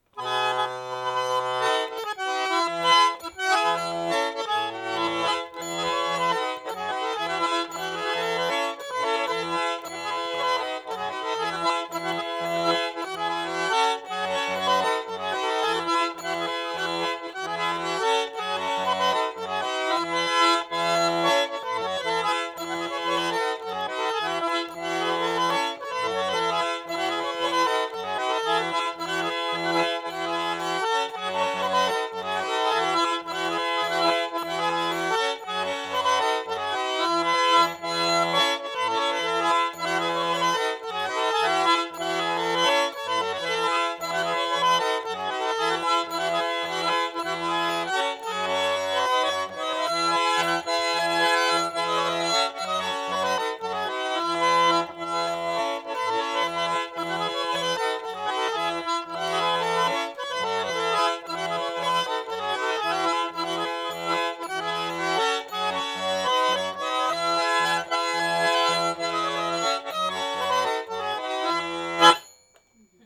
01 «Санчуринка» — наигрыш на румянцевской гармонике